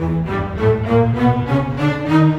Rock-Pop 20 Strings 02.wav